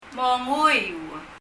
パラオ語 PALAUAN language study notes « Listen 聴く repeat 繰り返す » read 読む monguiu [mɔŋuyu] 英） read 日） 読む Leave a Reply 返信をキャンセルする。